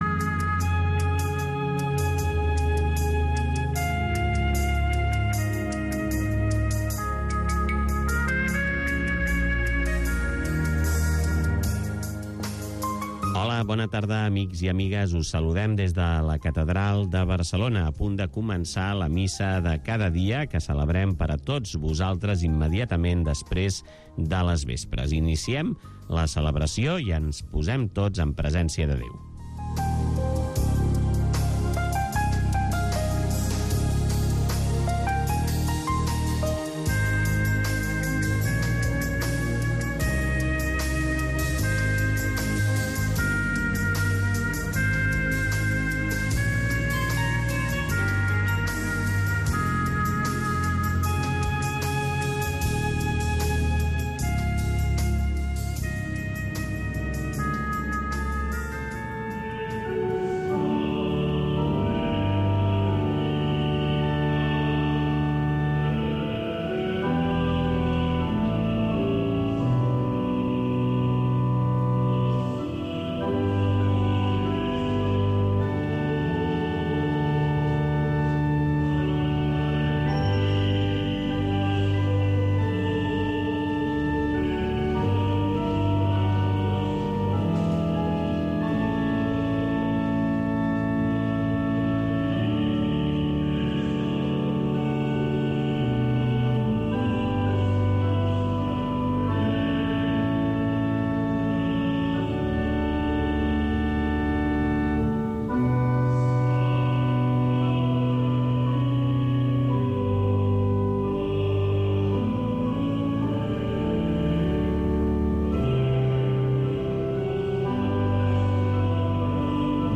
La Missa de cada dia. Cada dia pots seguir la Missa en directe amb Ràdio Estel.